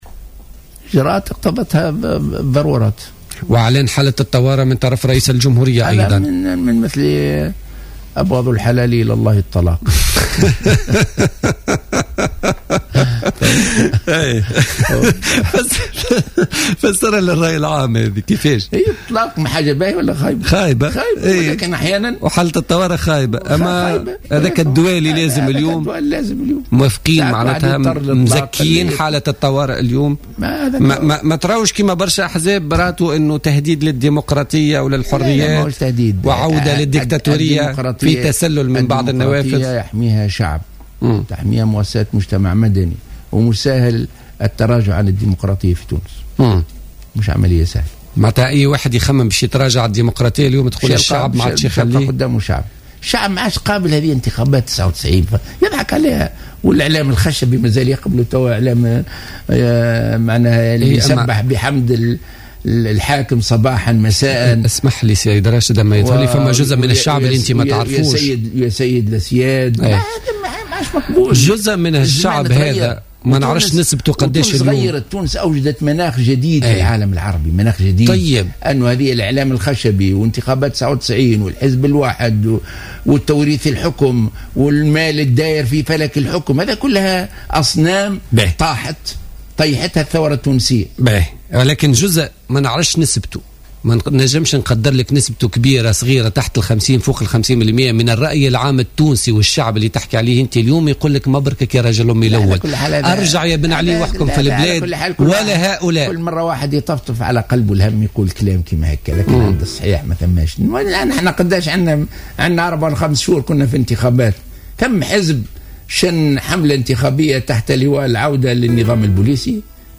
وصف زعيم حركة النهضة راشد الغنوشي في حوار مع إذاعة الجوهرة اليوم الخميس، قرار رئيس الجهورية بإعلان حالة الطوارئ بأنه "أبغض الحلال" ومثله كمثل الطلاق إلا أنه كان شرا لا بد منه في ظل الأوضاع التي تمر بها البلاد.